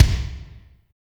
THUNDER K.wav